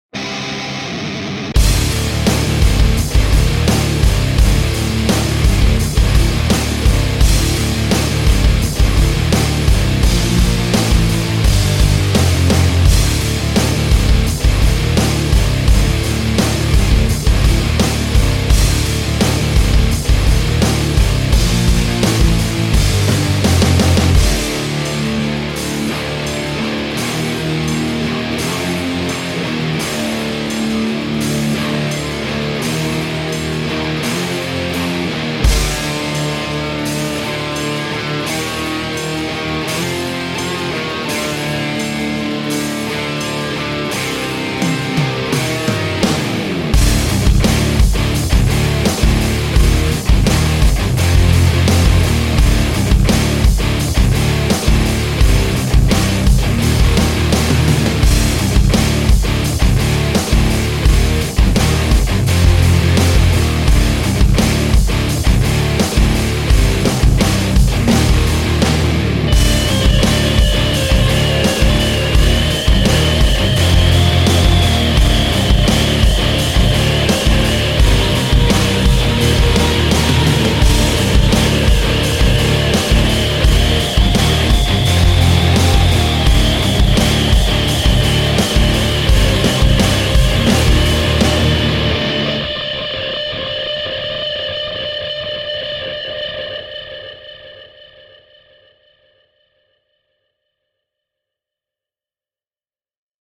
Nu Metal